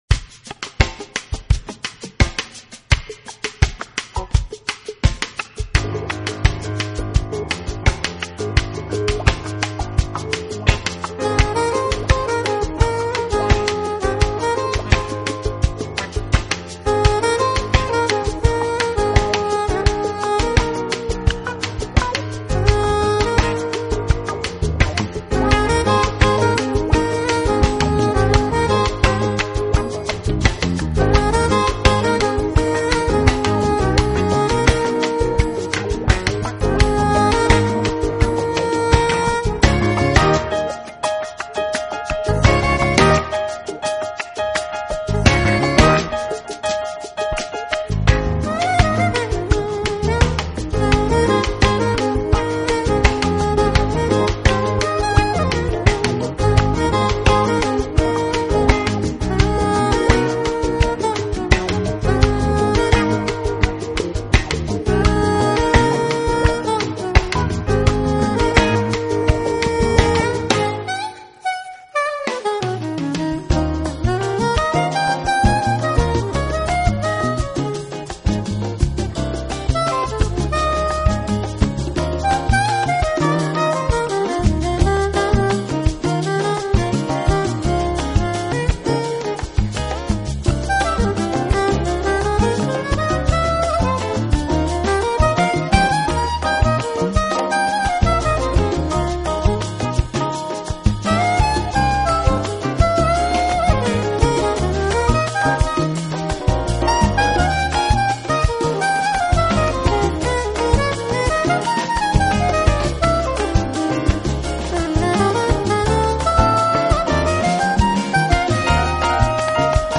风格：Smooth Jazz
听起来还是很有节日气氛的。